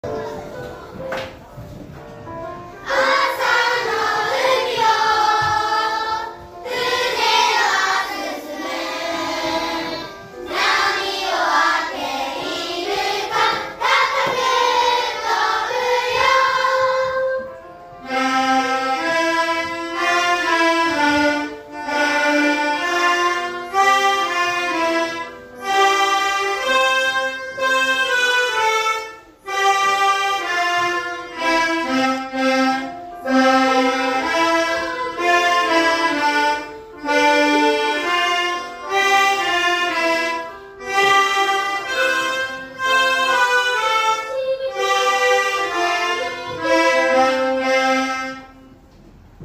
音でます♪海風きって 3年生